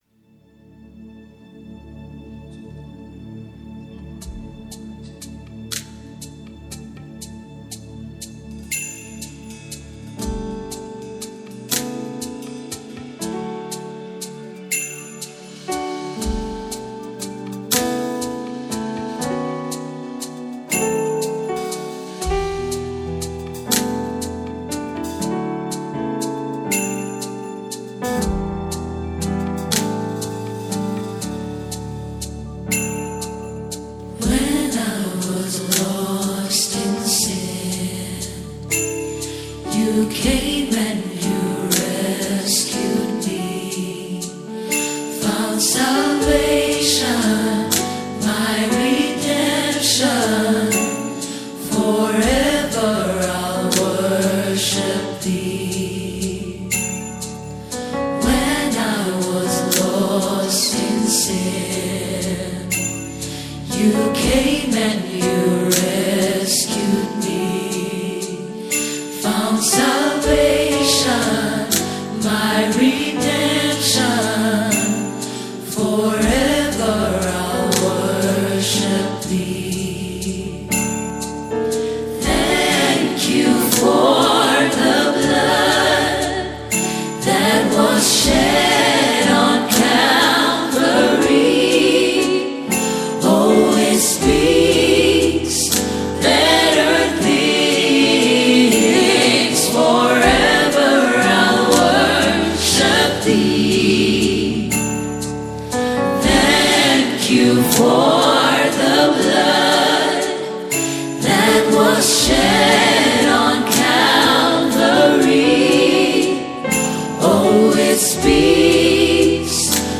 soul-stirring anthem